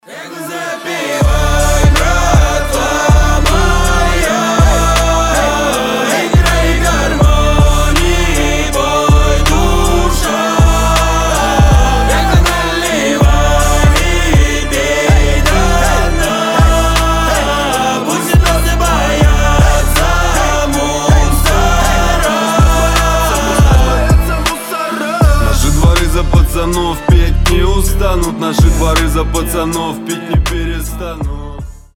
• Качество: 320, Stereo
дворовые